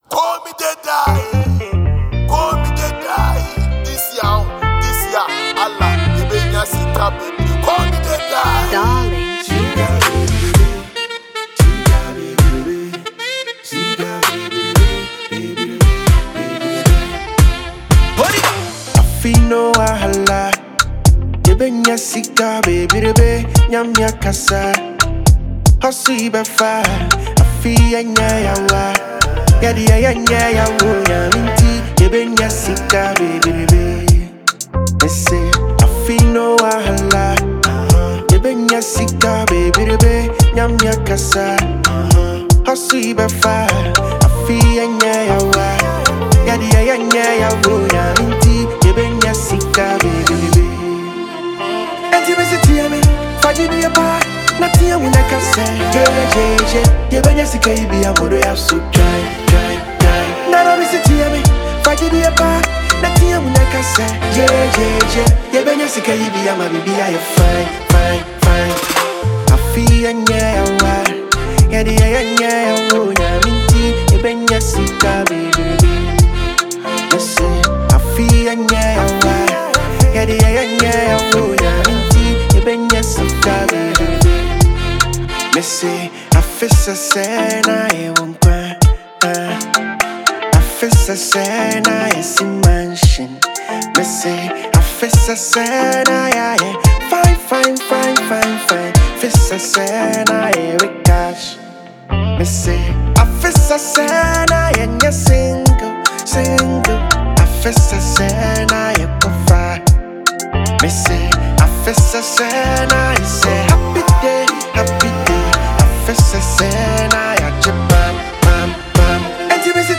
Ghanaian rapper and songwriter
” a determined and uplifting record built on focus